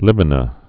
(lĭmə-nə)